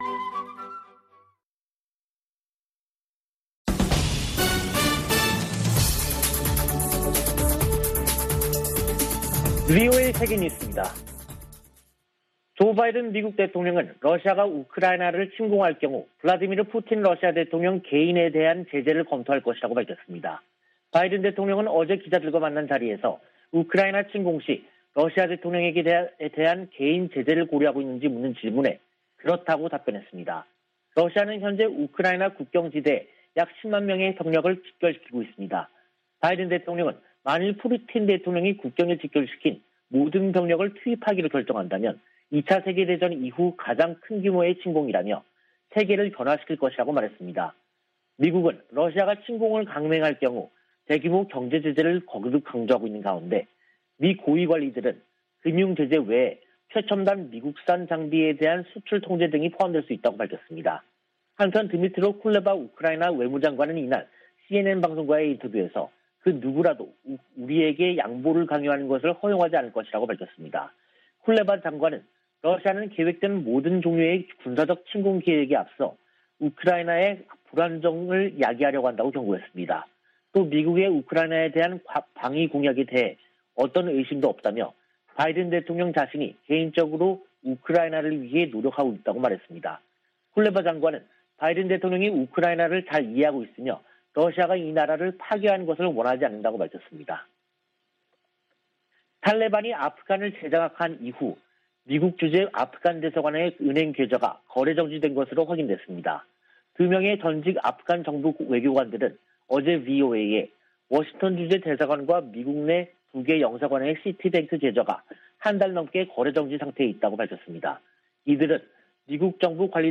VOA 한국어 간판 뉴스 프로그램 '뉴스 투데이', 2022년 1월 26일 2부 방송입니다. 미 국무부는 북한이 순항미사일 2발을 발사한 것과 관련, 여전히 평가 중이라고 밝히고, 대북 정책 목표는 여전히 한반도의 완전한 비핵화라고 확인했습니다. 북한의 잇따른 미사일 발사는 평화와 안정을 위협한다고 백악관 국가안보회의 부보좌관이 밝혔습니다. 유엔은 북한이 올해 5번째 무력 시위를 강행한데 대해 한반도 긴장 완화를 위한 대화를 촉구했습니다.